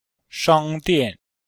S1-L9-shang1dian4.mp3